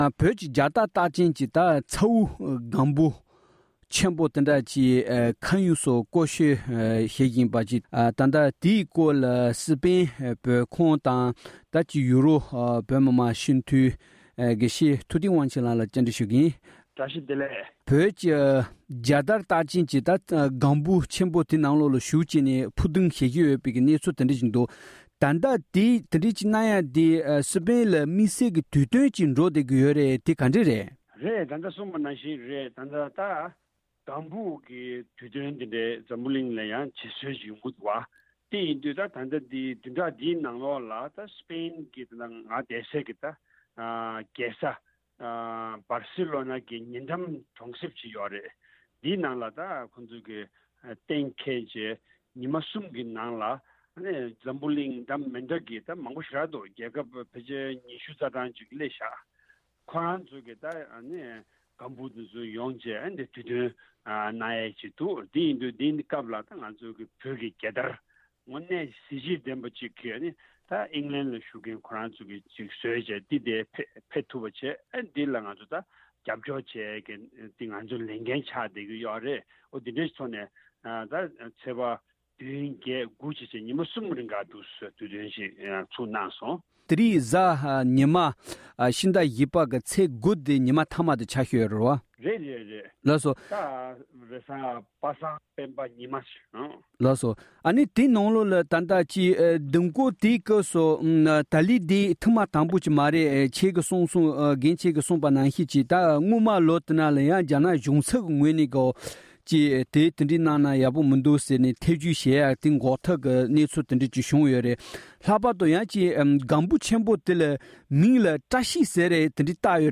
བཅའ་འདྲི་ཞུས་པ།